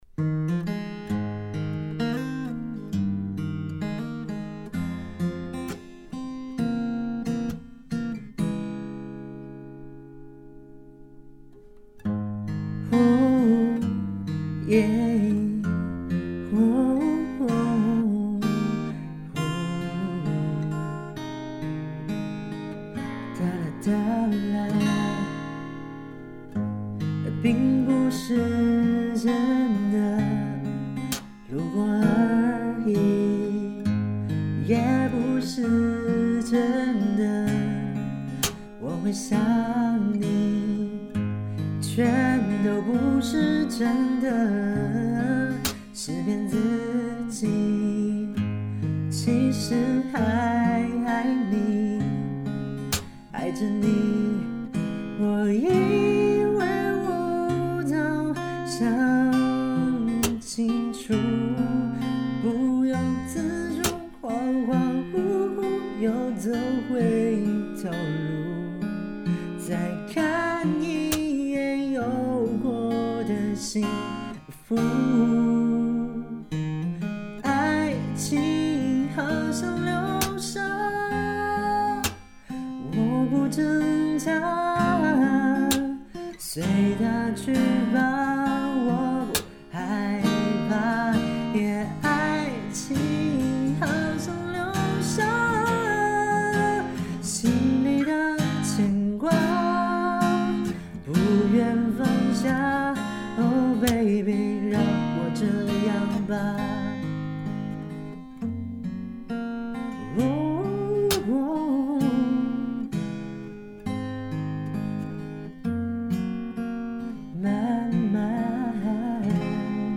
变调夹/指法：1品G调指法
曲谱采用了G调指法，变调夹夹1品，重音移位，强拍由1/3拍转到了2/4拍的拍弦。